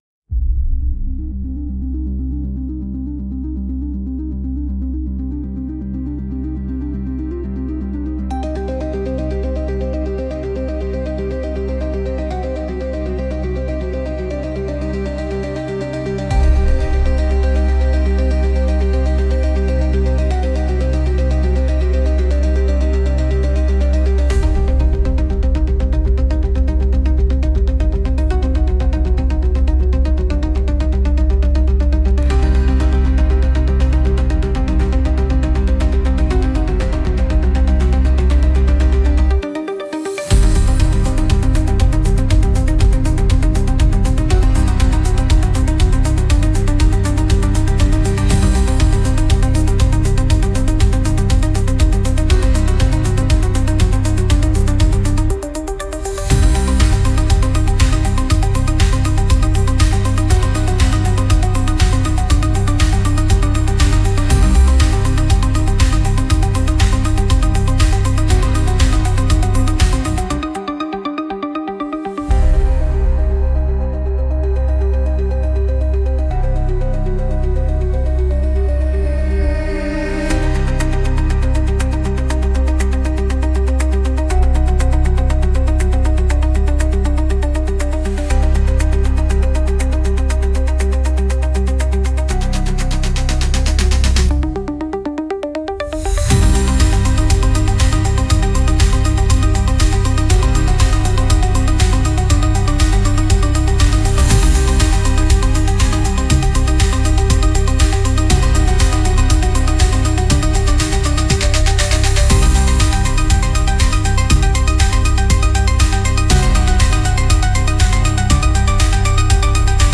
Instrumental - Throb Machine - 2.15 Mins